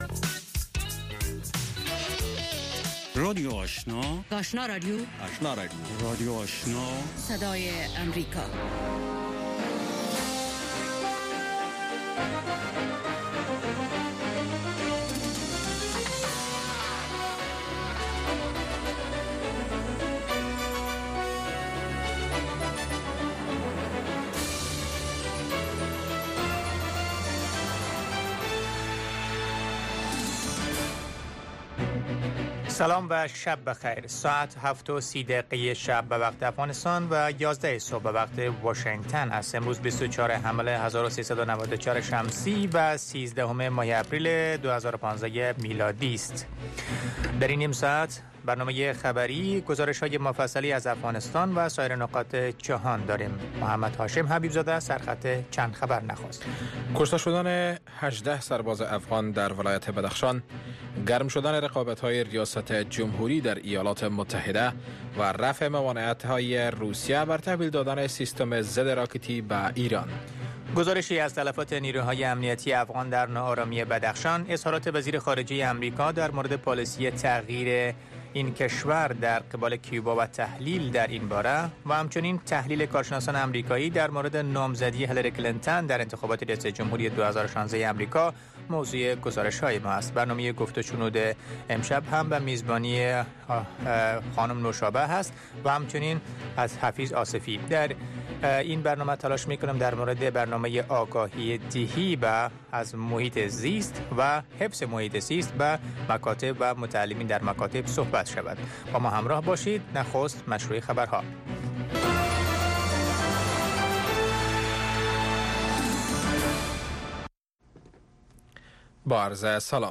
در اولین برنامه خبری شب، خبرهای تازه و گزارش های دقیق از سرتاسر افغانستان، منطقه و جهان فقط در سی دقیقه.